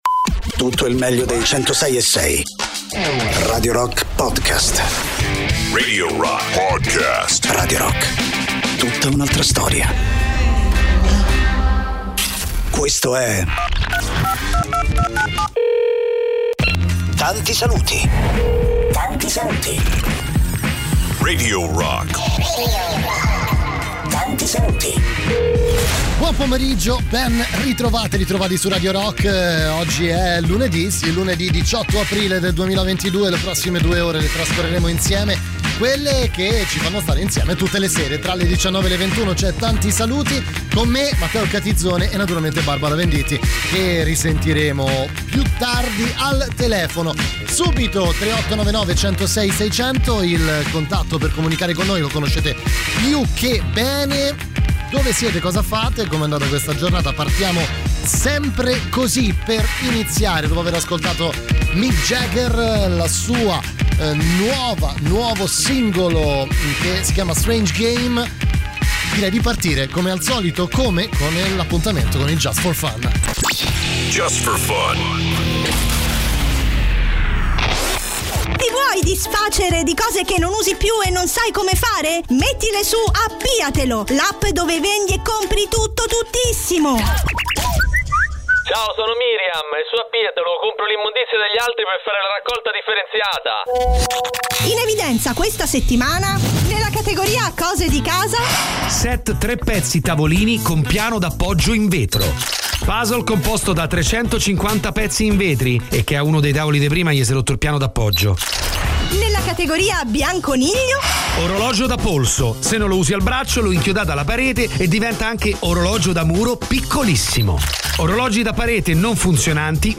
in diretta dal lunedì al venerdì, dalle 19 alle 21, con “Tanti Saluti” sui 106.6 di Radio Rock